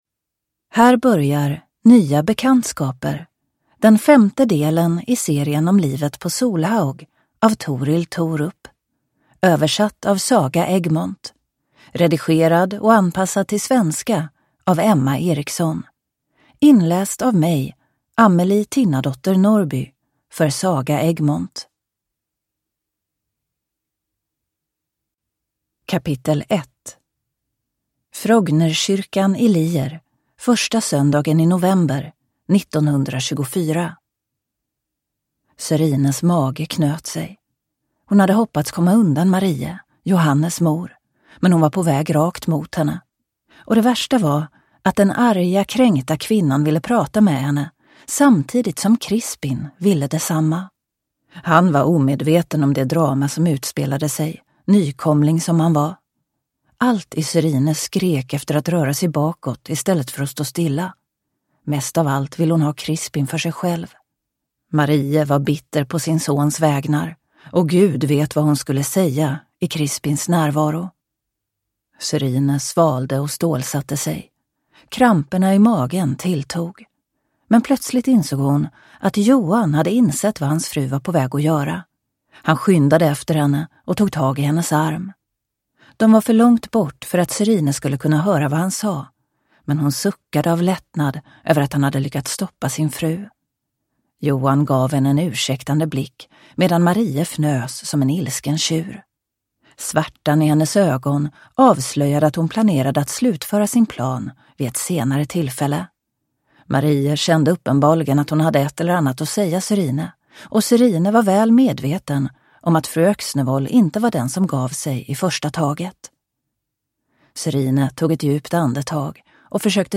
Nya bekantskaper (ljudbok) av Torill Thorup